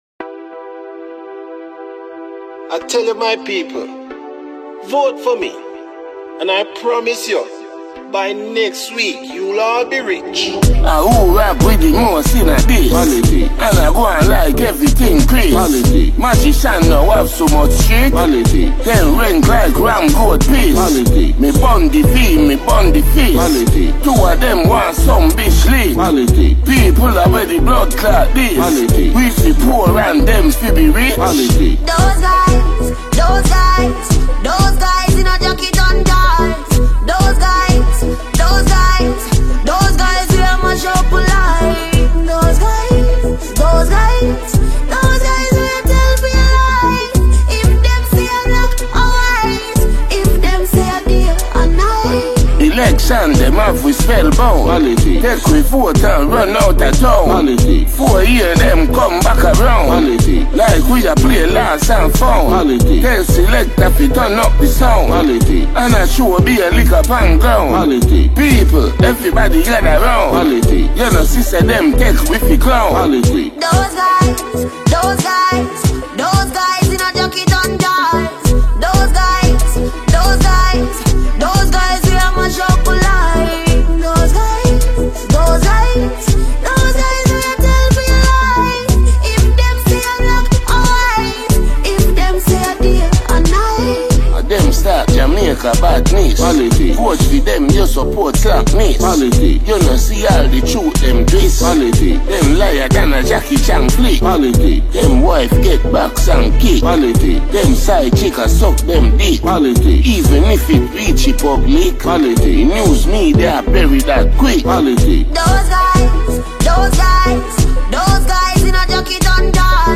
Jamaican reggae dancehall recording artist
reggae/dancehall